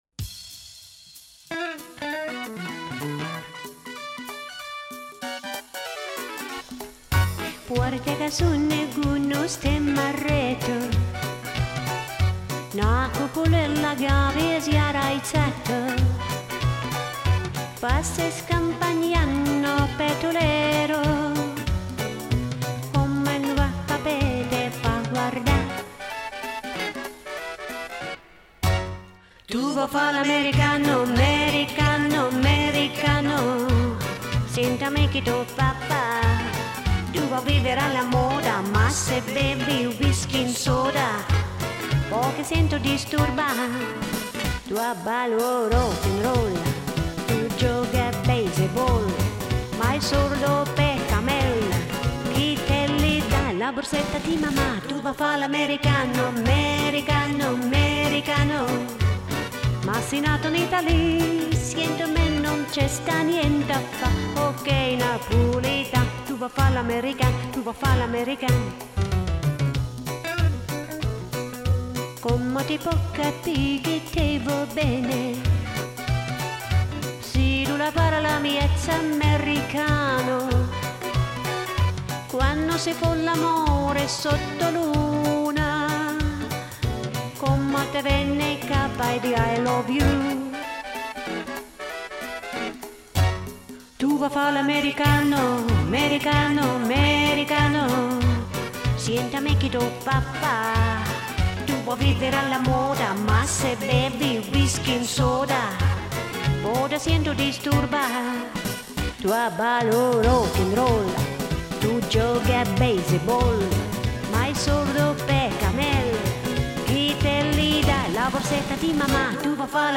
Я постаралась поймать его акцент...
С итальянским проще.. а здесь неаполитанский диалект..
с оттенком такого изящного ретро.